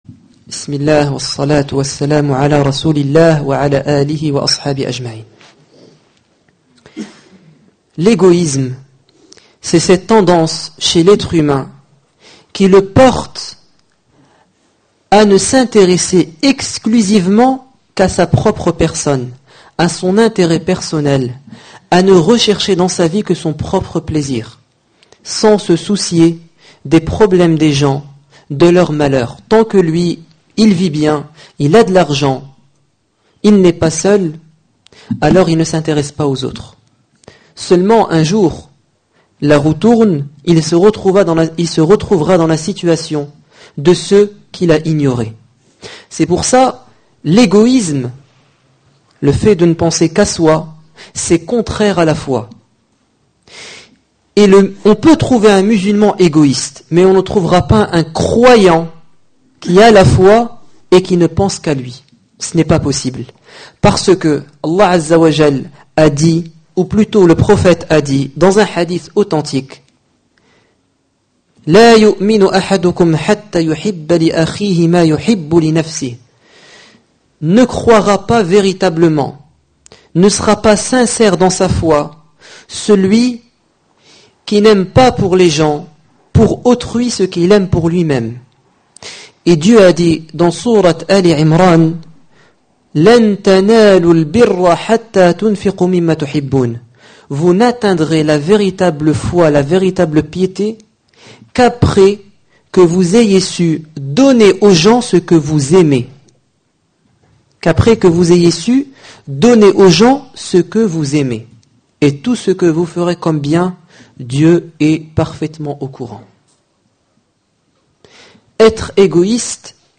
Discours du 26 octobre 2012
Discours du vendredi